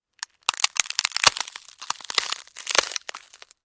На этой странице собраны звуки перелома костей – от резких щелчков до глухих тресков.
Звук медленно ломающихся костей